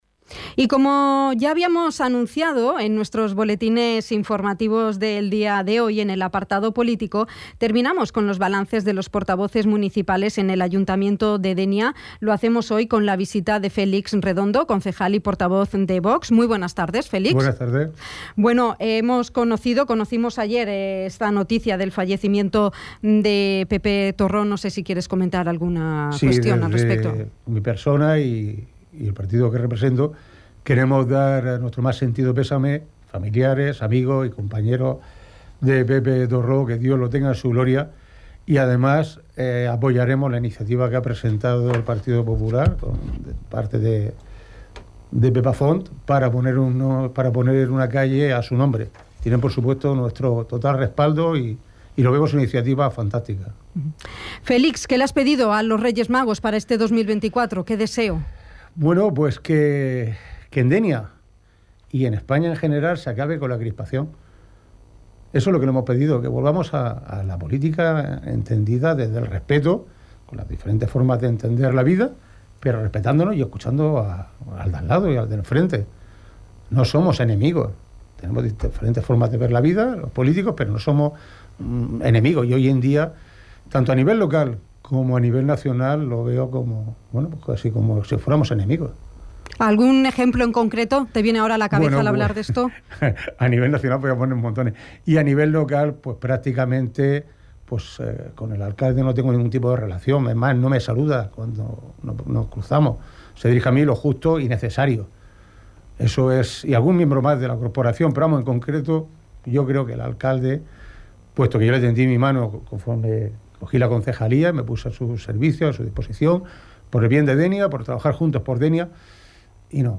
El portavoz y concejal del grupo municipal Vox en el Ayuntamiento de Dénia, Félix Redondo ha visitado la redacción informativa de Dénia FM, para realizar balance político del año 2023 dejado atrás, y propósitos y deseos para este 2024.
Entrevista-Felix-Redondo.mp3